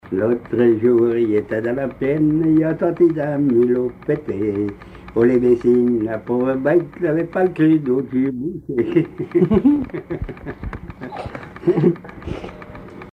Genre énumérative
Catégorie Pièce musicale inédite